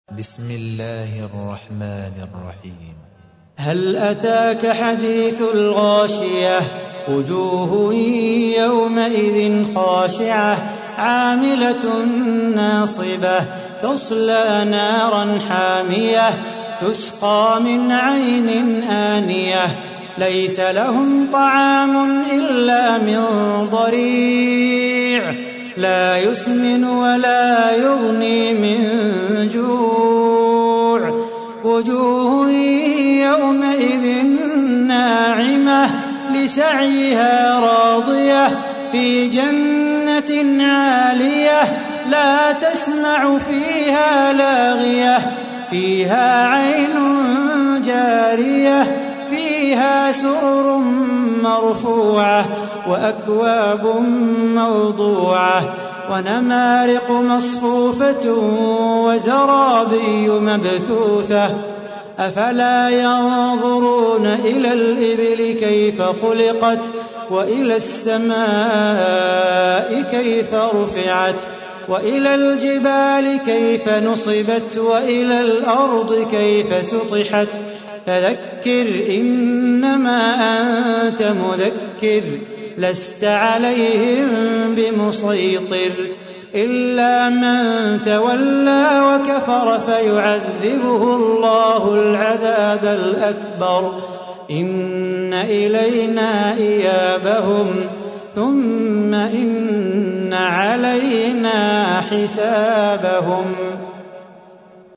تحميل : 88. سورة الغاشية / القارئ صلاح بو خاطر / القرآن الكريم / موقع يا حسين